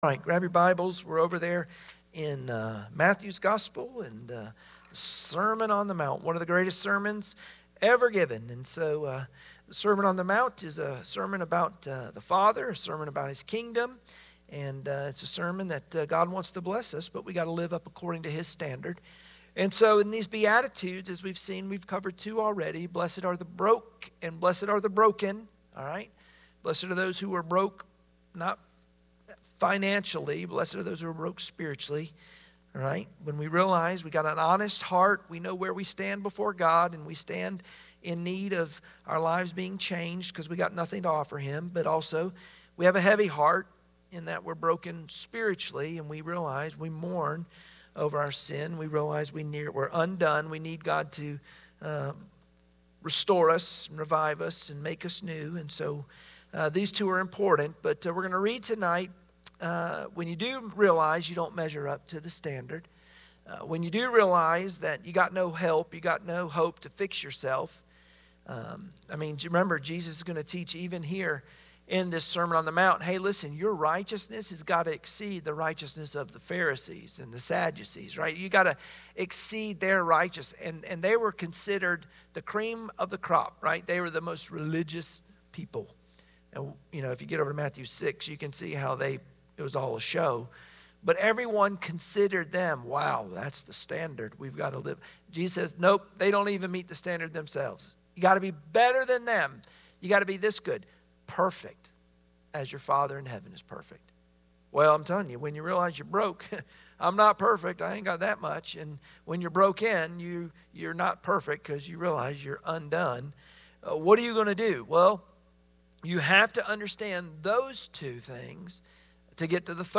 Matthew 5:6 Service Type: Wednesday Prayer Meeting Share this